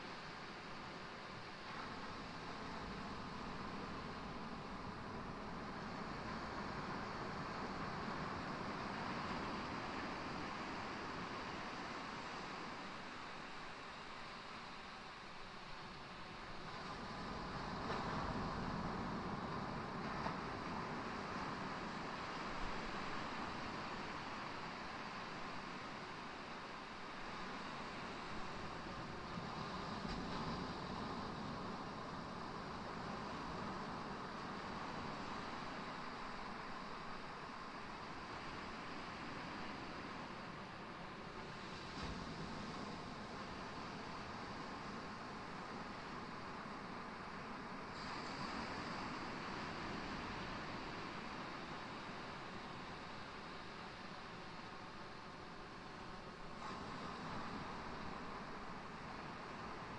死亡的海岸 " SEAIA BEACH AB 2M
Tag: 大洋 大海 沙滩 海浪